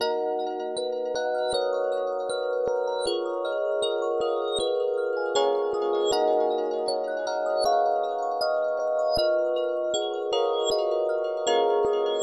它是157个bmp。
Tag: 157 bpm Hip Hop Loops Bells Loops 2.06 MB wav Key : A